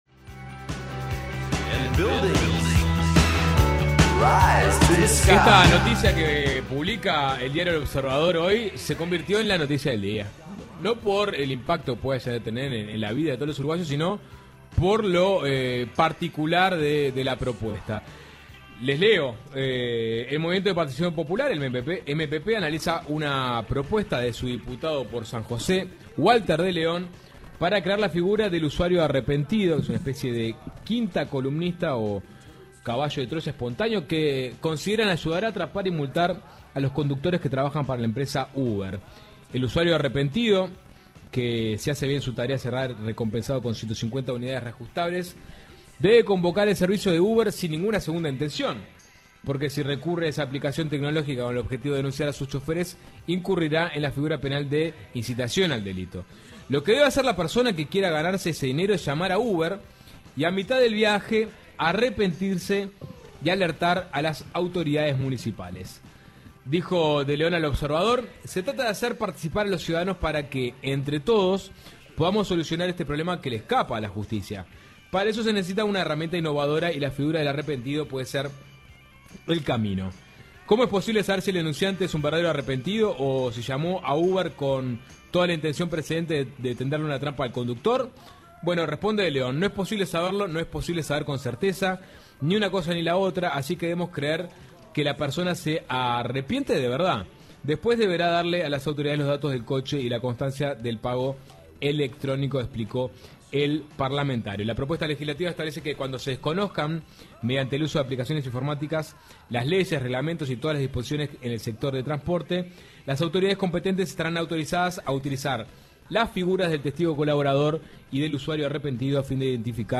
¿De dónde saldría el dinero que se pagaría a los "usuarios arrepentidos" de Uber? ¿Se está promoviendo la actividad ilegal? de esto hablamos con el diputado del MPP Walter de León.